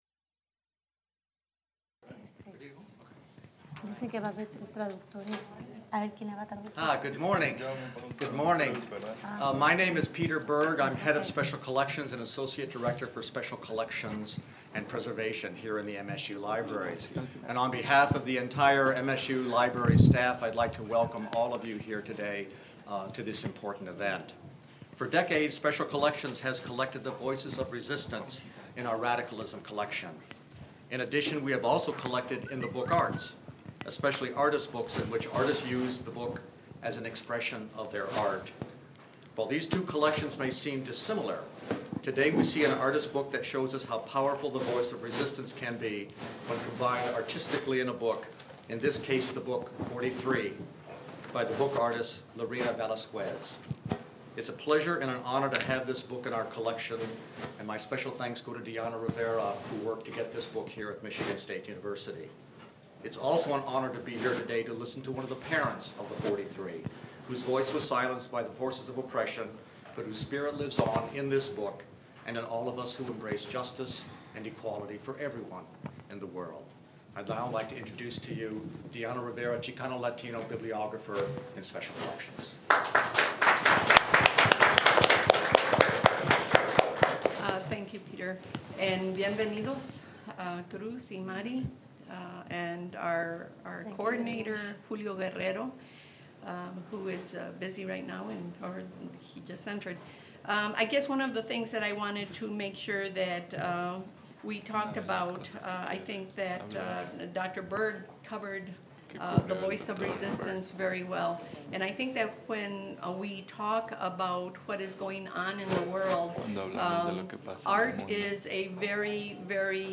The speakers answer questions from the audience.
English to Spanish translation can be heard.
Held in the MSU Main Library.